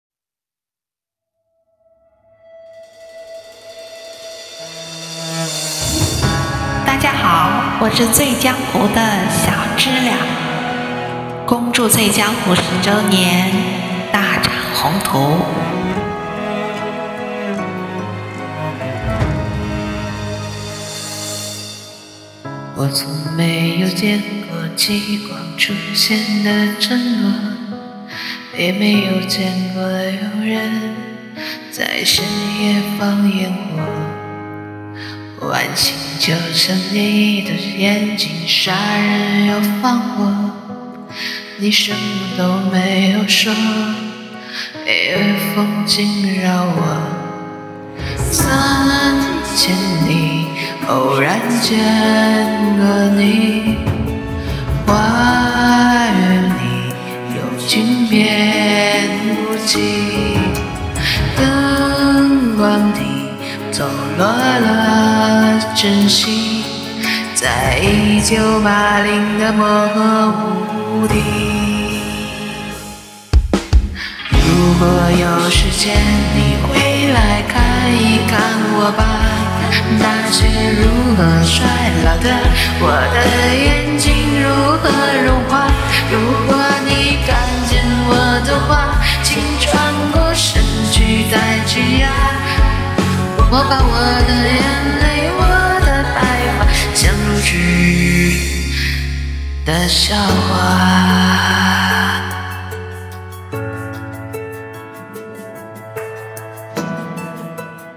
所以，我选择了自己最不擅长的低沉音色来演绎！
P.S: 耳机坏了，对着手机可是使了大劲儿的！真的挺影响音色和情感上的输出，敬请海涵！